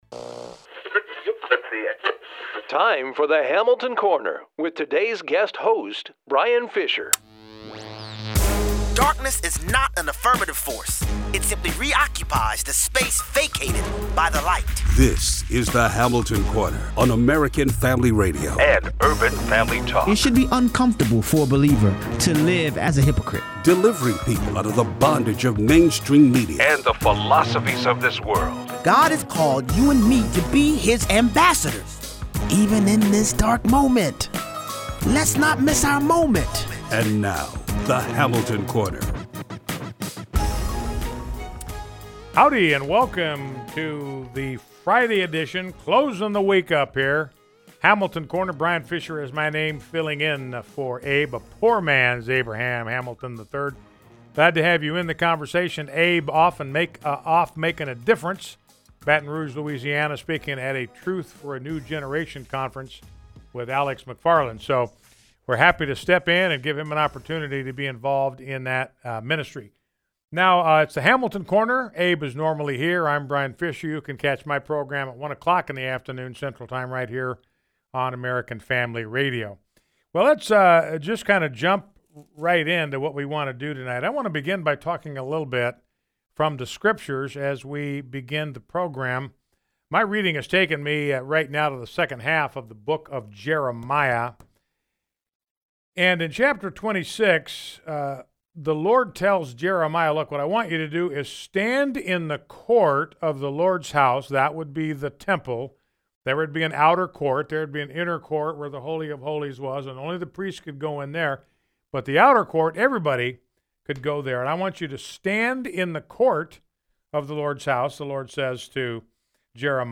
Guest Host
Be sure to teach the WHOLE counsel of God - don't hold back a word 0:23 - 0:40: Mika Brzenzski finally says something I agree with 0:43 - 0:60: Callers agree: President Trump needs to seal the border